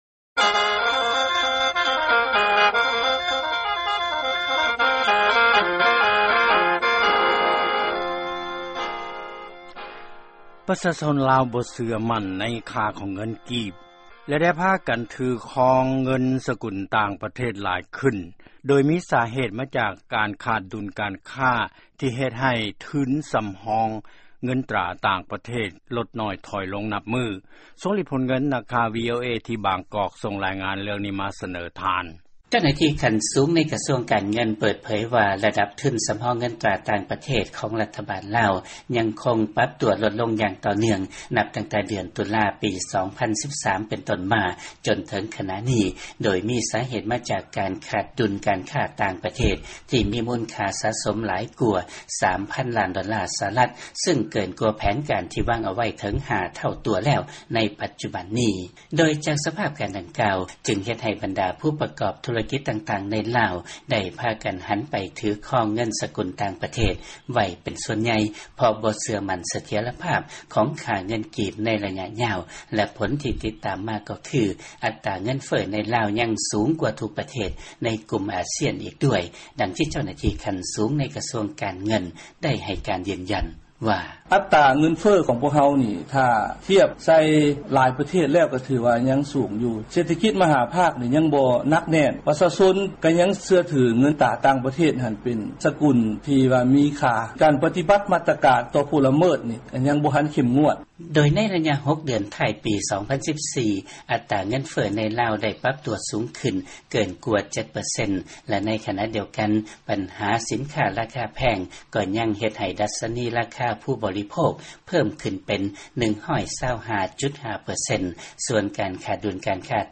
ຟັງລາຍງານ ປະຊາຊົນລາວ ບໍ່ເຊື່ອໝັ້ນ ໃນຄ່າເງິນກີບ ແລ້ວພາກັນຖື ຄອງເງິນສະກຸນ ຕ່າງປະເທດຫຼາຍຂຶ້ນ.